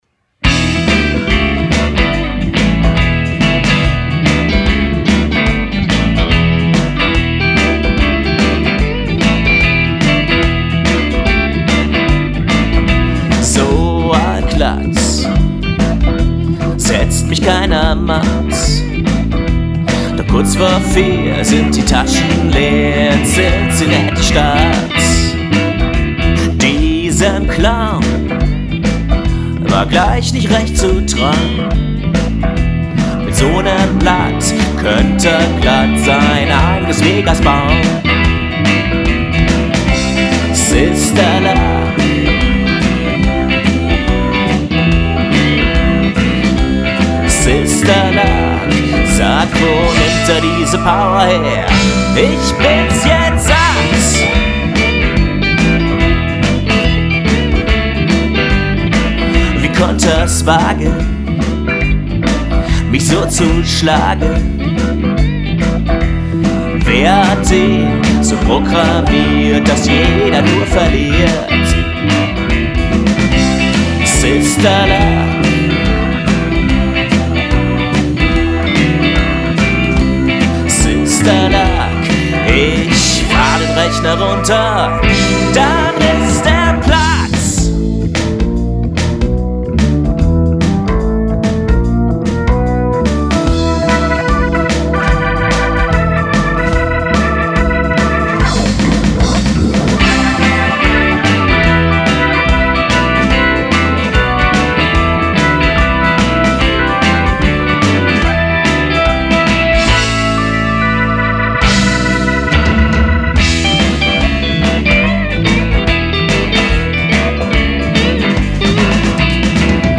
Akustik